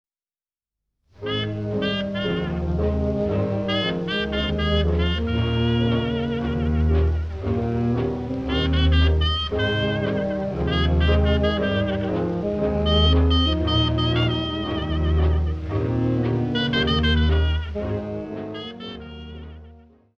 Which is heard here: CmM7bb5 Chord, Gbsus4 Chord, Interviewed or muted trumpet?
muted trumpet